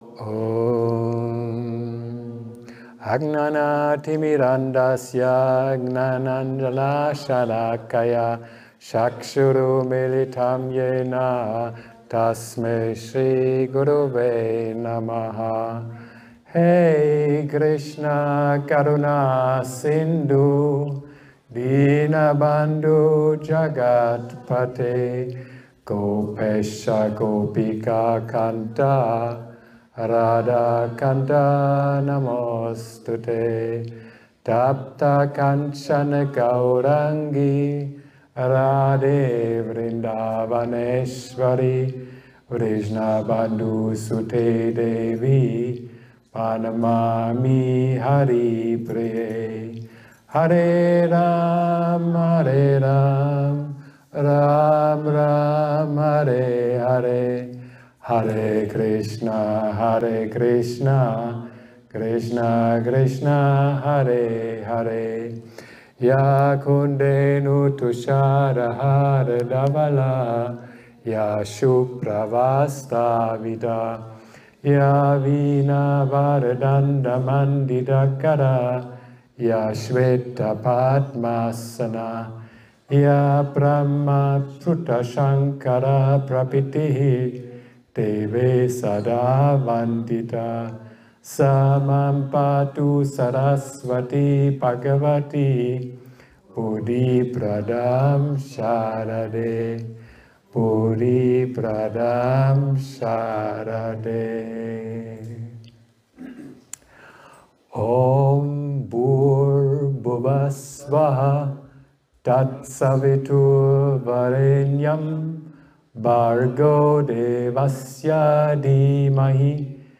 Jeder kennt das Gayatri Mantra, aber wer ist Gayatri? Willkommen zum Gayatri Satsang anlässlich der 24 Stunden Gayatri Yagna in Sri Vitthal Dham.